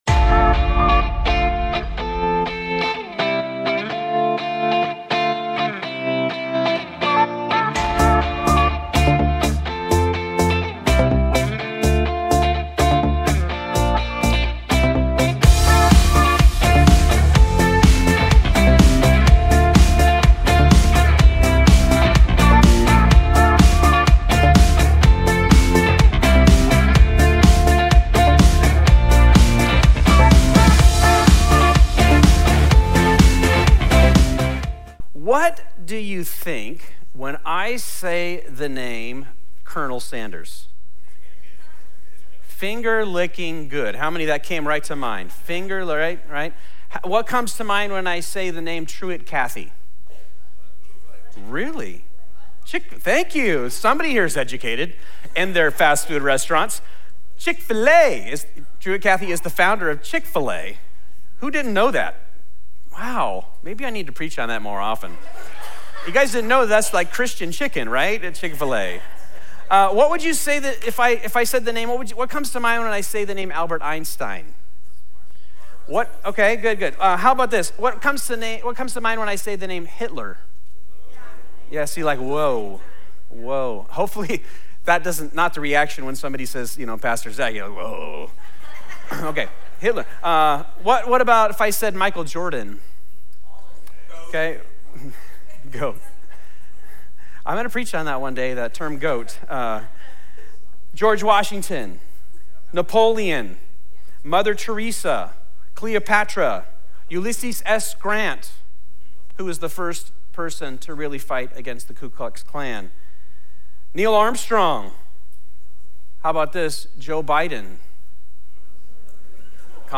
This video is the opening sermon in our new series at Fusion Christian Church called “Why Jesus.”
Faith Jesus Sunday Morning This video is the opening sermon in our new series at Fusion Christian Church called "Why Jesus."